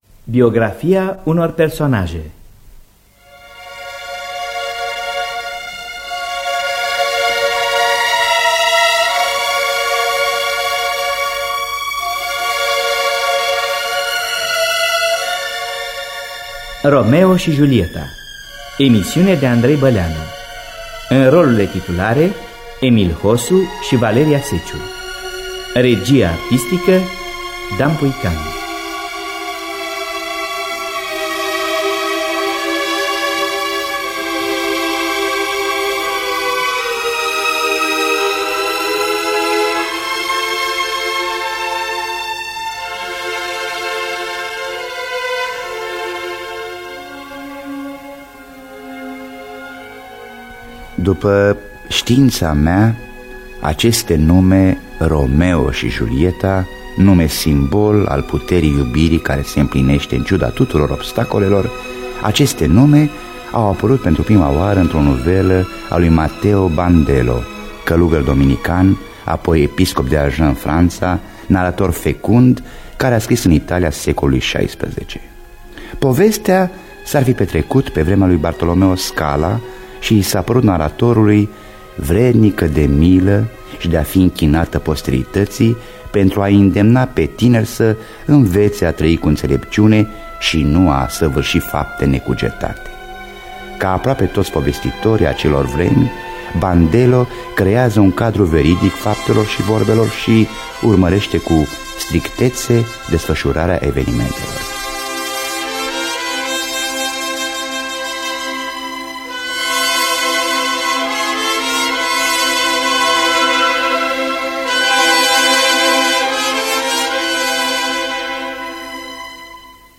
Biografii, memorii: Romeo și Julieta. Scenariu radiofonic de Andrei Băleanu.
Înregistrare din anul 1979.